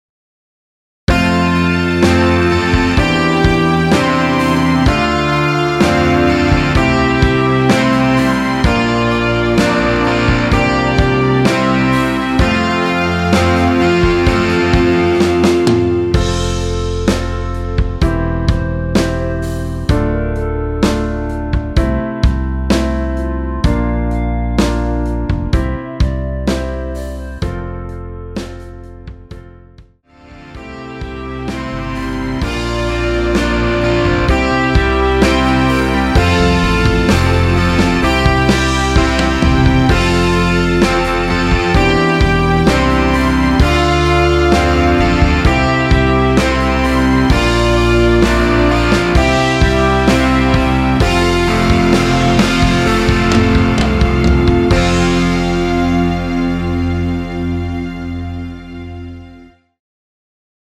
원키에서(-2)내린 멜로디 포함된 MR입니다.
앞부분30초, 뒷부분30초씩 편집해서 올려 드리고 있습니다.
(멜로디 MR)은 가이드 멜로디가 포함된 MR 입니다.